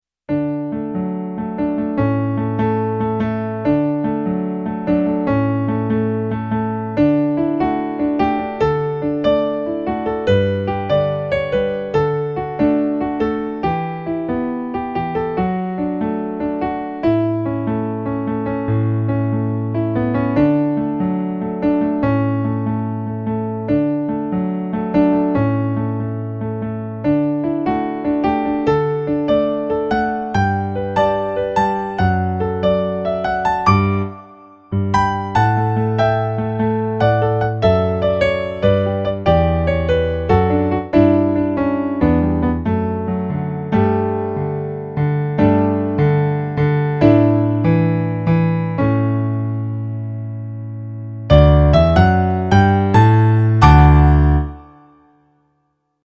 contains eight piano solo arrangements.
calypso remix